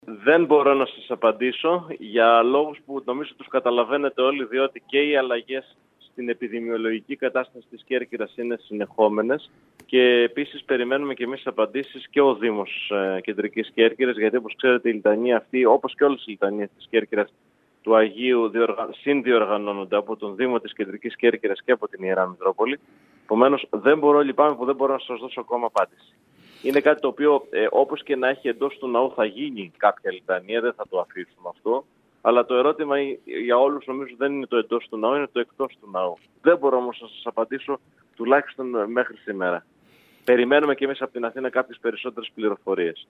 Όπως μας είπε μιλώντας σήμερα στην ΕΡΤ Κέρκυρας, τόσο ο Δήμος Κέρκυρας όσο και η Ιερά Μητρόπολη περιμένουν διευκρινίσεις από την Πολιτική Προστασία και την Αρχιεπισκοπή. Το σίγουρο είναι ότι θα τελεστεί περιφορά του Ιερού σκηνώματος μέσα στην εκκλησία.